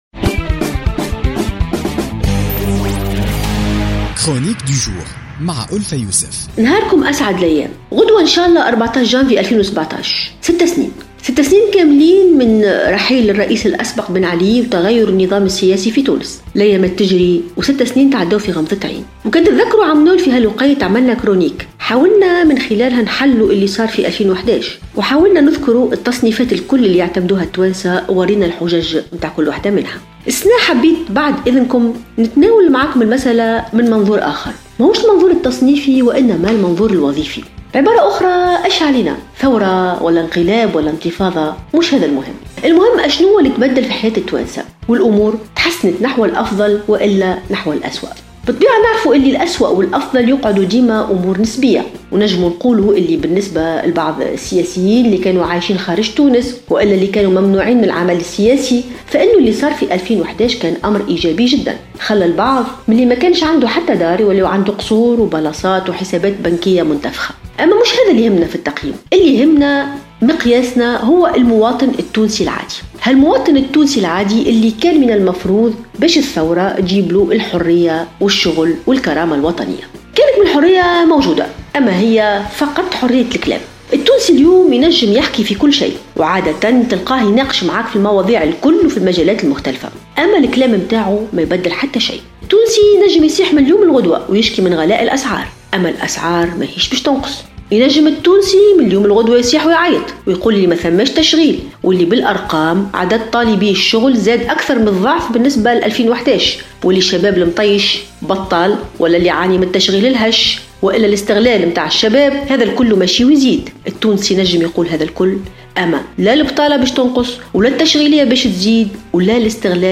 تطرقت الكاتبة ألفة يوسف في افتتاحية اليوم الجمعة 13 جانفي 2017 إلى مرور 6 سنوات على رحيل الرئيس الأسبق بن علي وتغير نظام الحكم في تونس .